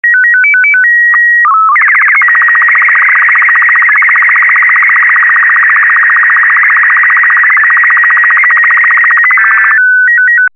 Robot_8_BW.mp3